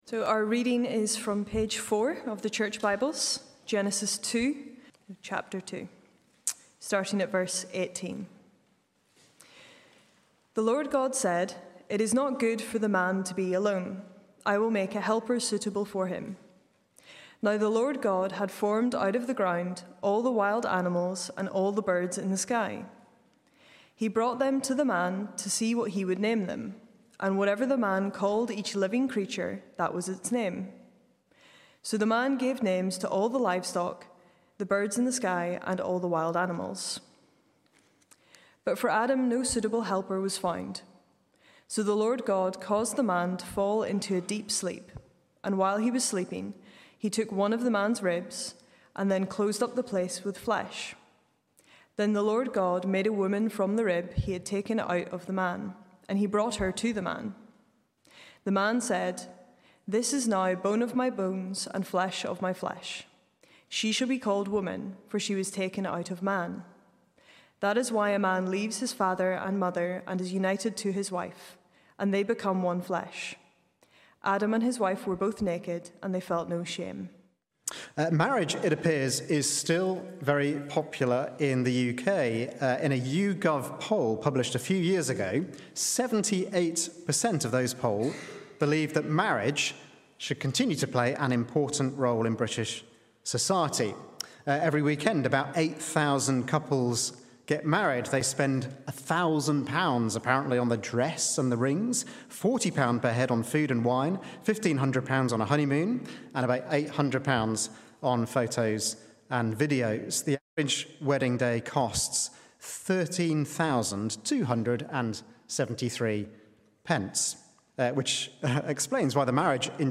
Sermons Archive - Page 16 of 187 - All Saints Preston